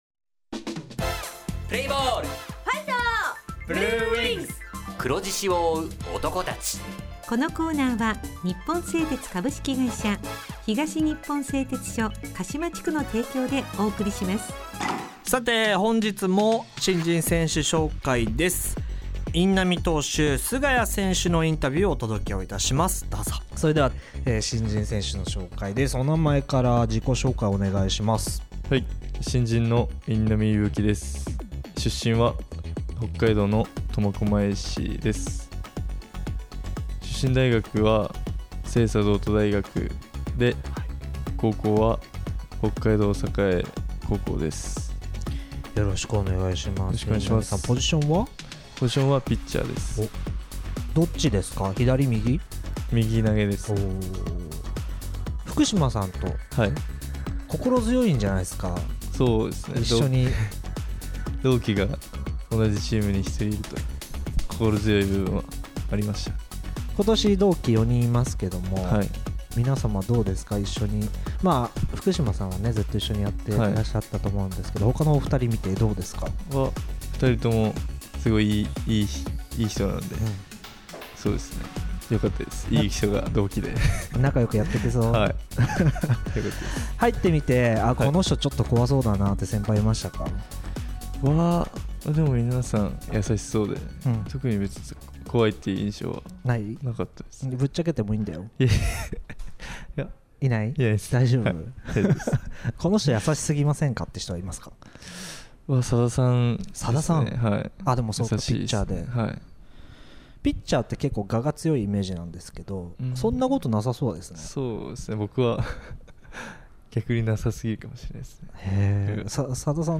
インタビュー
地元ＦＭ放送局「エフエムかしま」にて鹿島硬式野球部の番組放送しています。
《新人選手インタビュー》